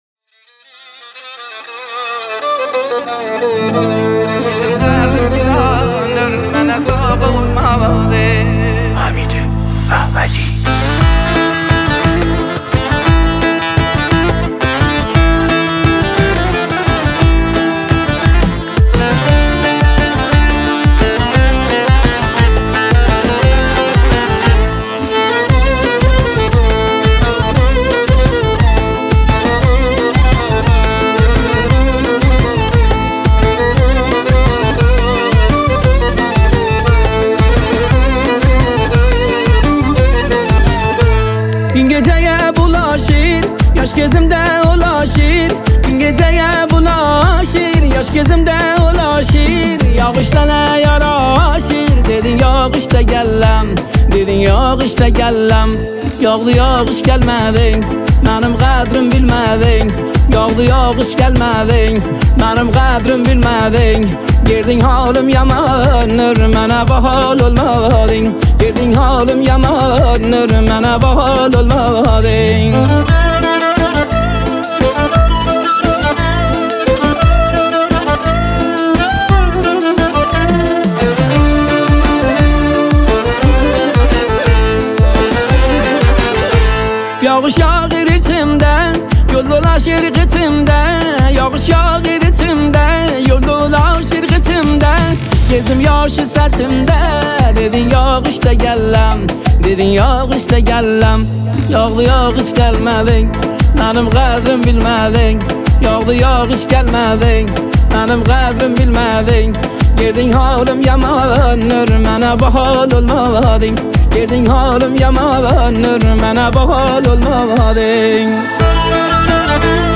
موزیک شاد ترکی
باغلاما
ویولن
تهیه شده در استودیو موسیقی